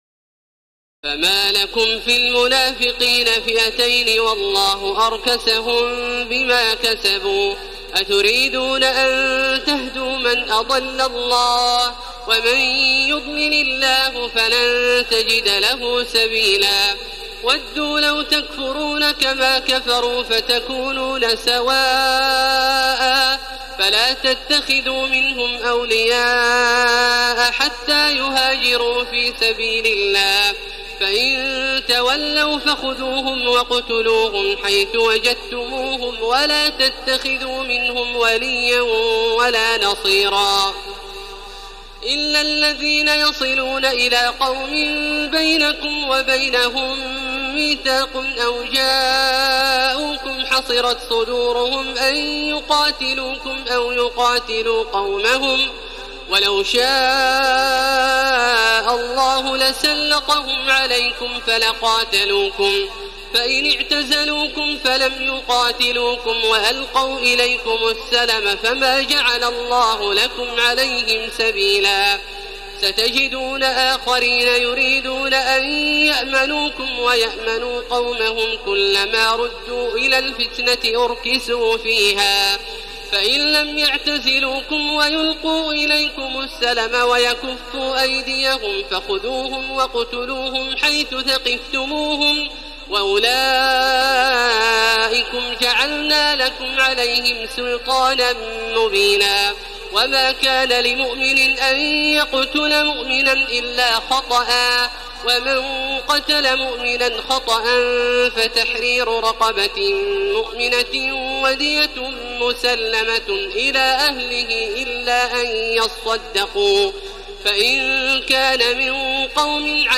تراويح الليلة الخامسة رمضان 1433هـ من سورة النساء (88-162) Taraweeh 5 st night Ramadan 1433H from Surah An-Nisaa > تراويح الحرم المكي عام 1433 🕋 > التراويح - تلاوات الحرمين